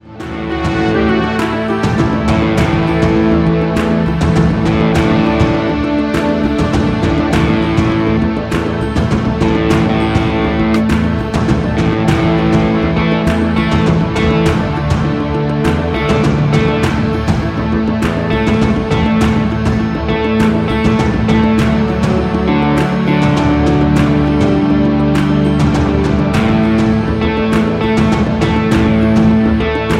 Backing track files: 1990s (2737)
Buy With Backing Vocals.
Buy With Lead vocal (to learn the song).